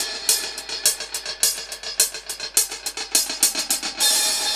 Index of /musicradar/dub-drums-samples/105bpm
Db_DrumsA_HatsEcho_105_03.wav